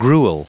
Prononciation du mot gruel en anglais (fichier audio)